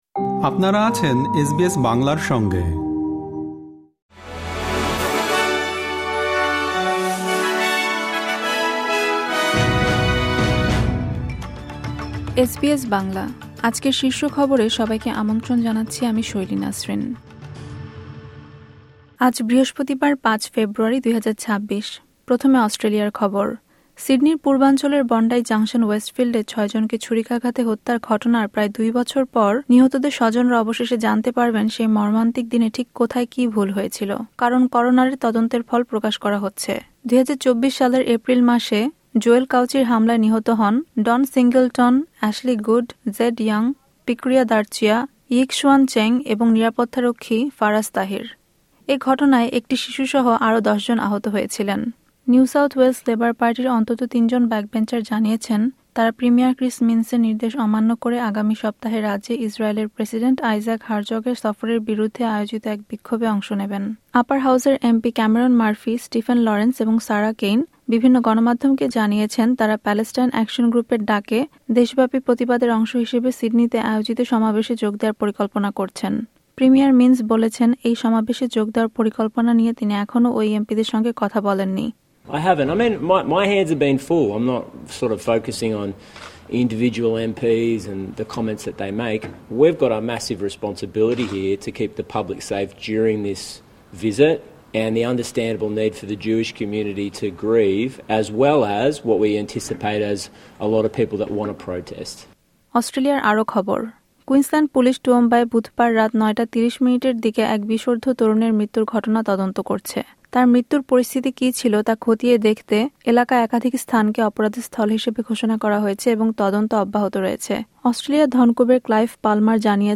এসবিএস বাংলা শীর্ষ খবর: গত বছর বন্ডাইয়ে ছয়জনকে হত্যার ঘটনায় অনুষ্ঠিত অনুসন্ধানের ফলাফল করোনার প্রকাশ করেছেন